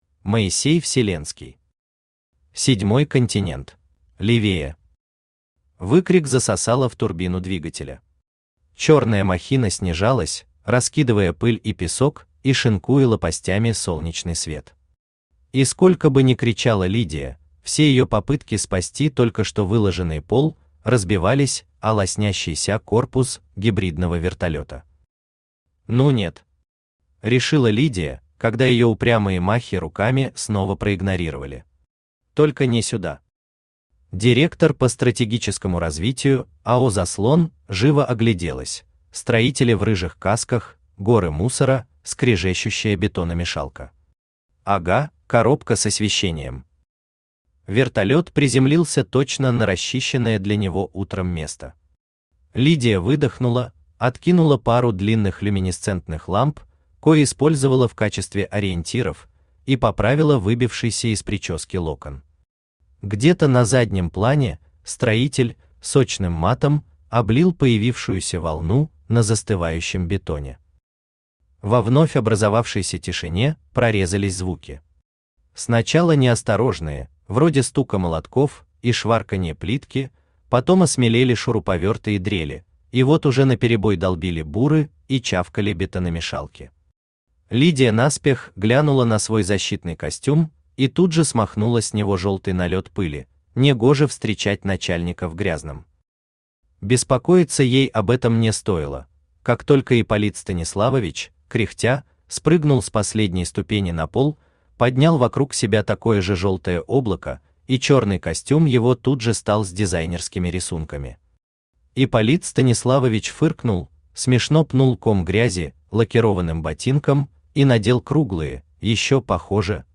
Аудиокнига Седьмой континент | Библиотека аудиокниг
Aудиокнига Седьмой континент Автор Моисей Вселенский Читает аудиокнигу Авточтец ЛитРес.